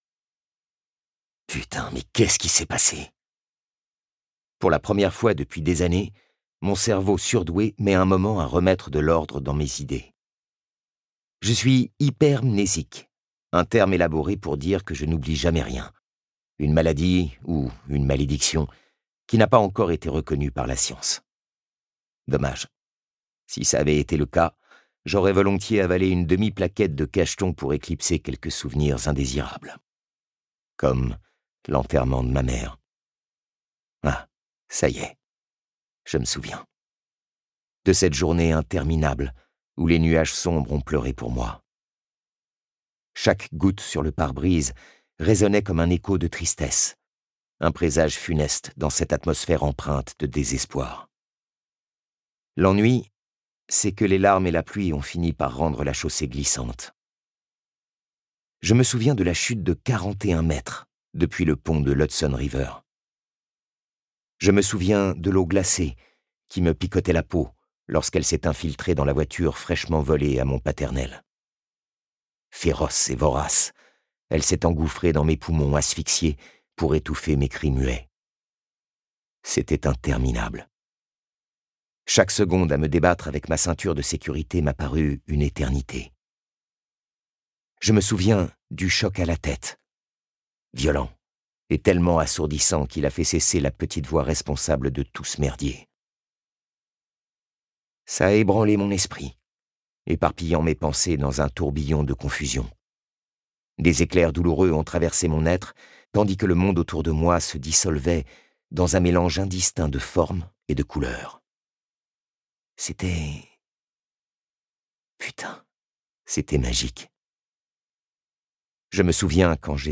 Le troisième et ultime tome de la saga phénomène Wattpad aux 3 millions de lecteurs débarque enfin dans vos oreilles !Fall Davies, infirmière, maladroite et bonne poire, n'a jamais menti de toute sa vie.